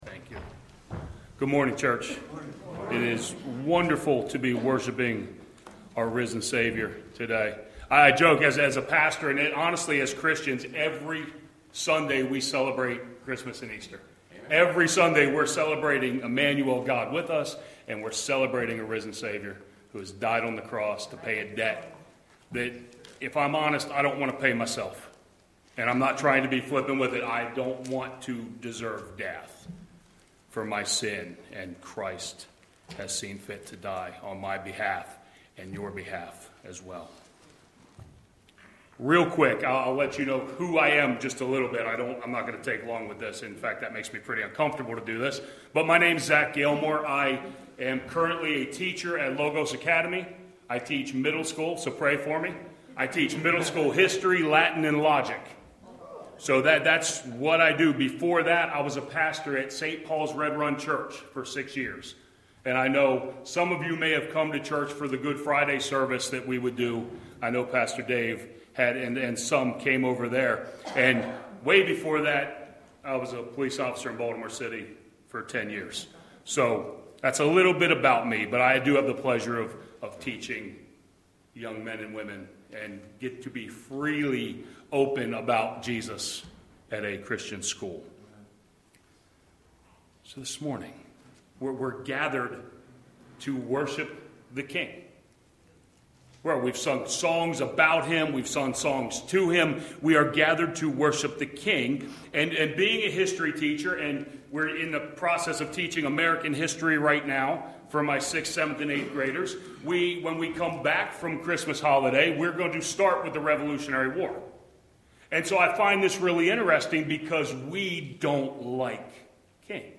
YBC Worship Service – 12/21/2025
Watch Online Service recorded at 9:45 Sunday morning.